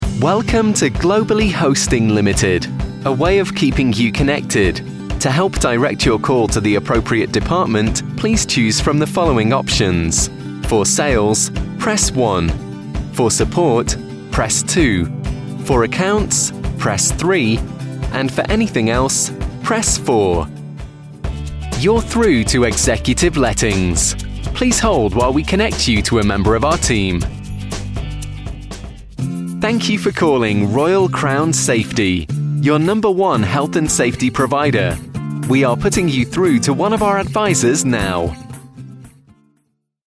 Hello! I am a professional English voice talent with a neutral or northern UK accent.